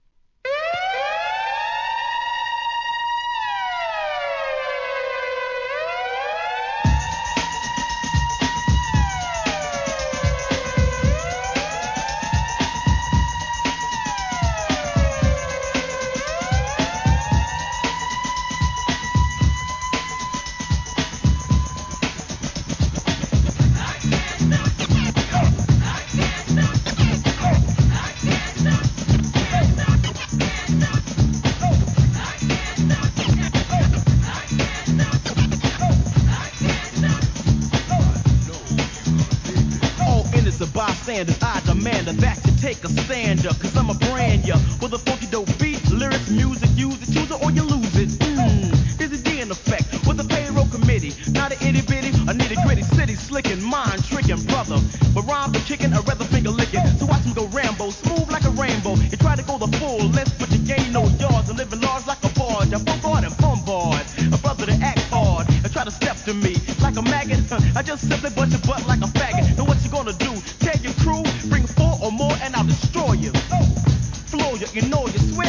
1. HIP HOP/R&B
スリリングなサイレン音が印象的な1990年、知る人ぞ知るミドル！